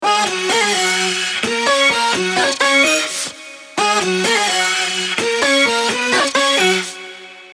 FUTURE BASS SAMPLE PACK
128BPM – G#min Vocal Loop FX